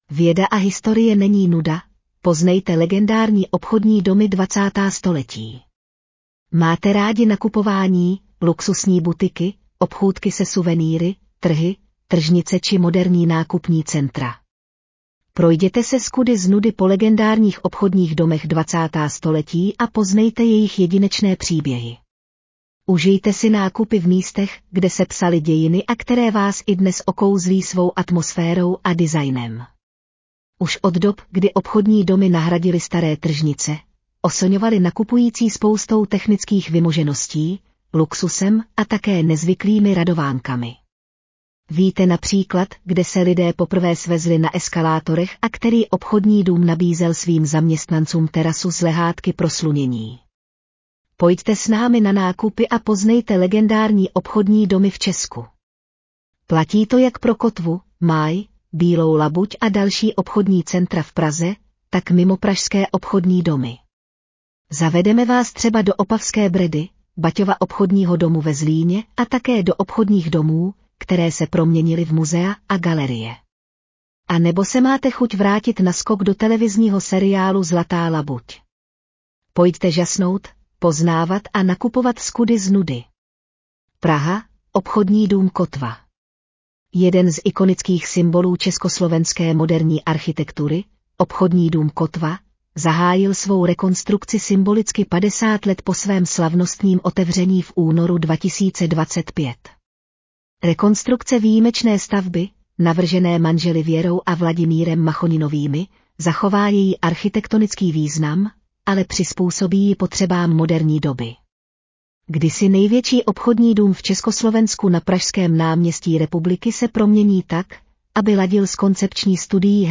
Audio verze článku Věda a historie není nuda: poznejte legendární obchodní domy 20. století